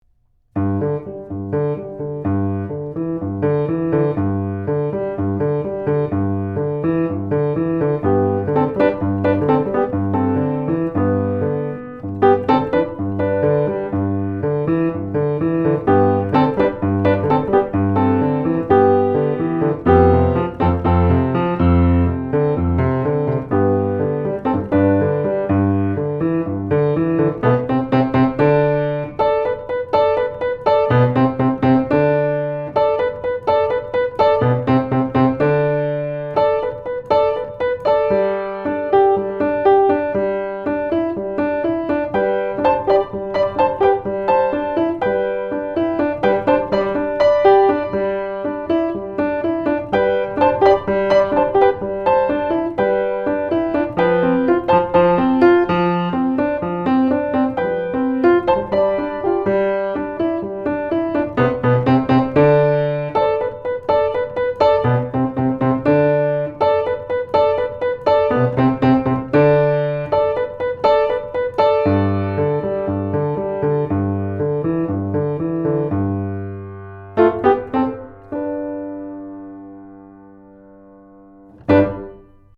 Here are several quick, 1-take MP3 sound files to give you an idea of what to expect. These MP3 files have no compression, EQ or reverb -- just straight signal, tracked with this Rode NT1-A mic into a TAB-Funkenwerk V78M preamp using a Sony PCM DI flash recorder.
SHAFER & SONS BABY GRAND
ShaferNT1aV78D1Piano2.mp3